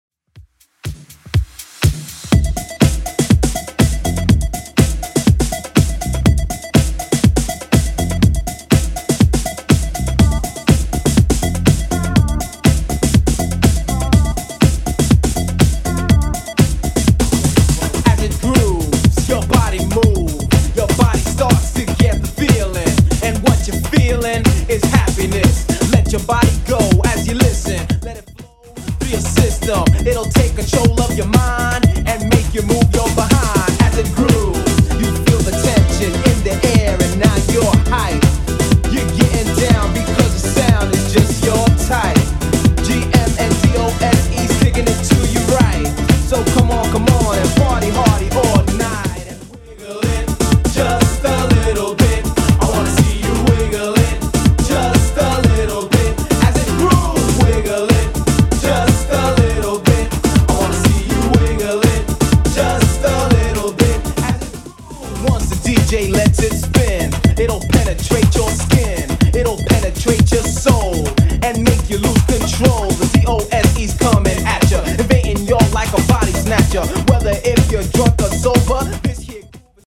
BOOTLEG , DANCE , TRAP